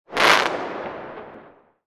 Firework Crackling Noise
Single firework rocket sound effect with clear crackling noise in the air. Perfect for isolating one pyrotechnic burst without background sounds.
Genres: Sound Effects
Firework-crackling-noise.mp3